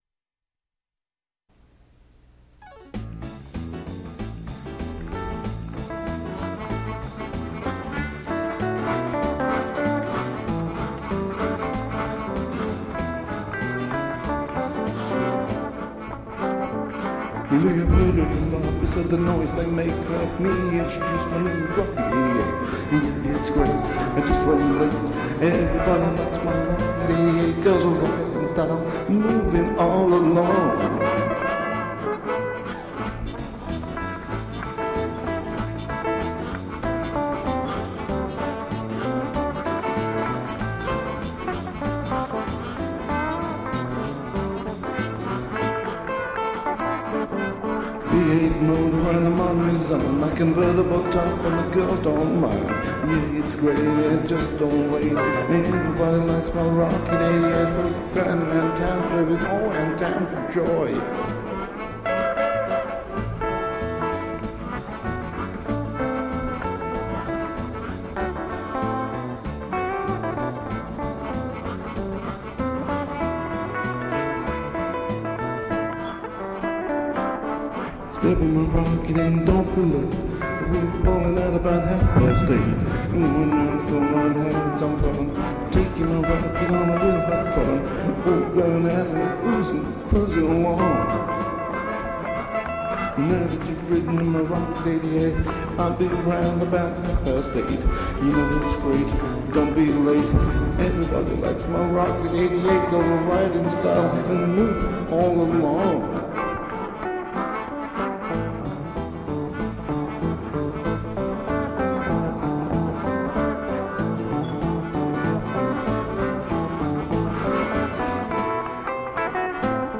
Country
Country-rock